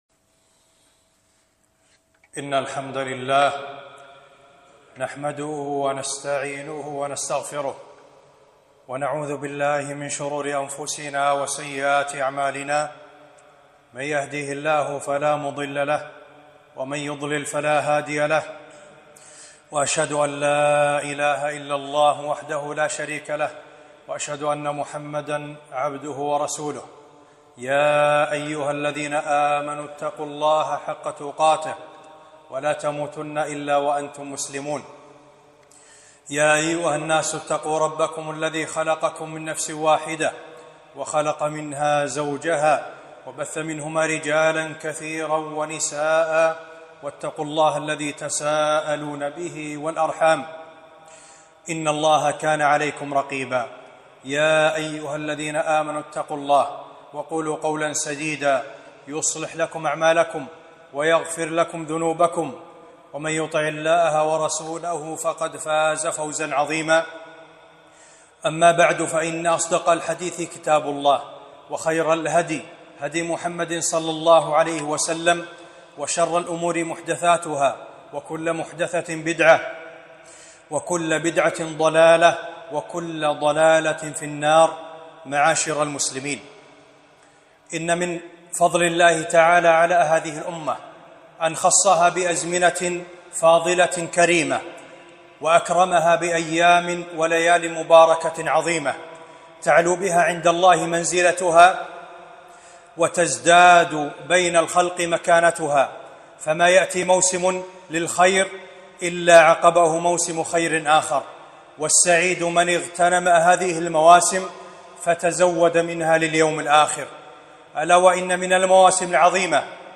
خطبة - شهر الله المحرم - وصيام يوم عاشوراء